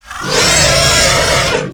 rogue_chimera_jump.ogg